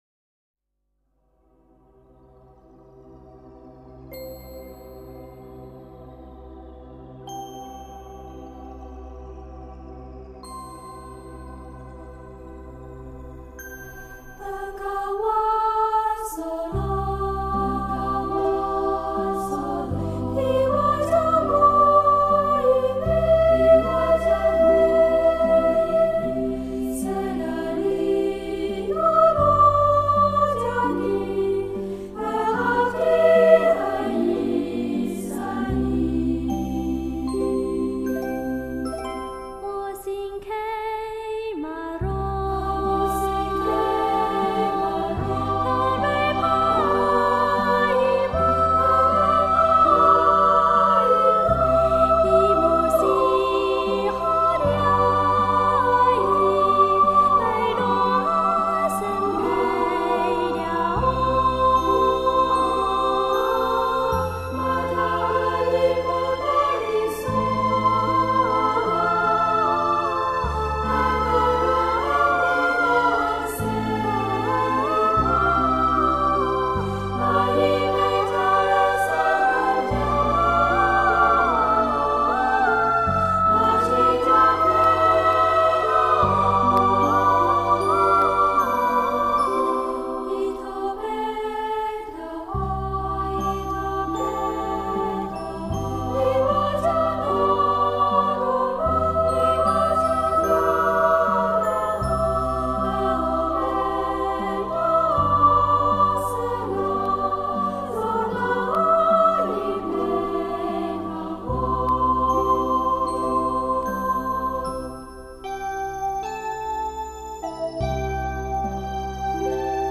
童声合唱是一种广受欢迎的形式，它包含错落的声部构成
与和谐悠扬的齐唱。